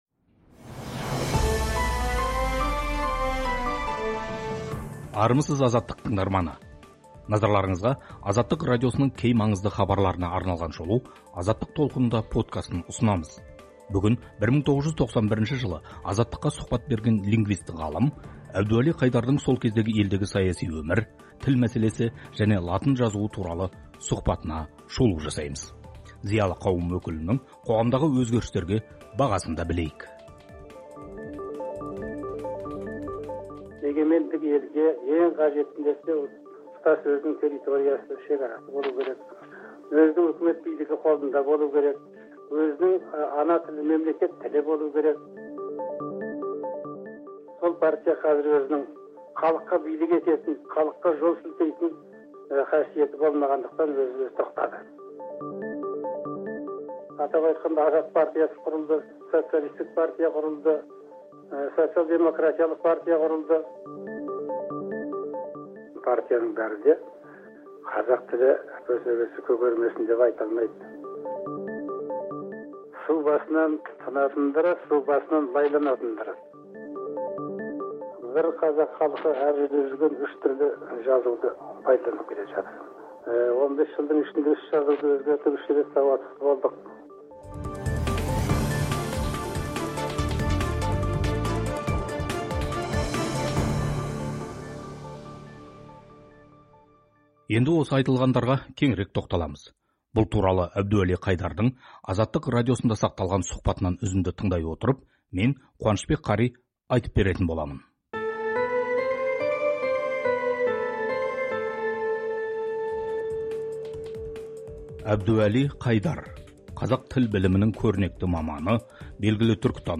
1991 жылы Азаттық радиосына берген сұхбатында этнолингвист ғалым Әбдуәли Қайдар елдегі саяси өмір, көппартиялық қоғамға қадам, тіл мәселесі және латын жазуы туралы ойын бөліскен. Зиялы қауым өкілінің қоғамдағы өзгерістерге берген бағасы – Азаттық радиосының эфирінде.